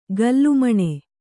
♪ gallu maṇe